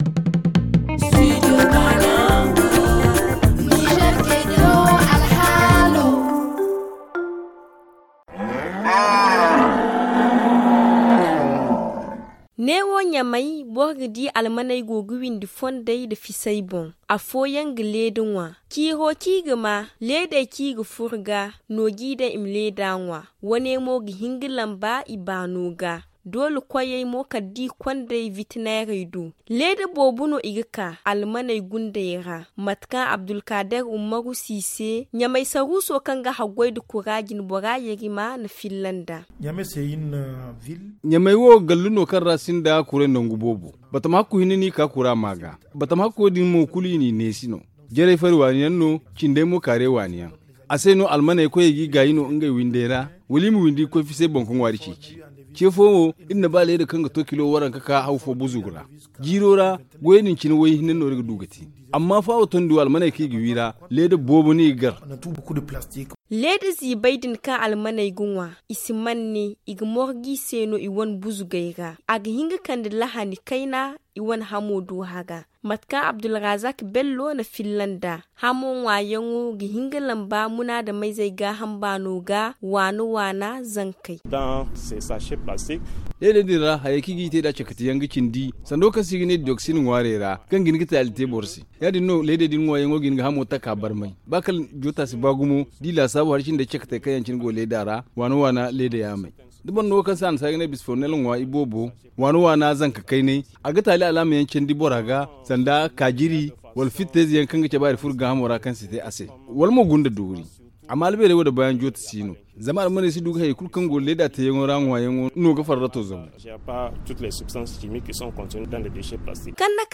Les détails dans ce reportage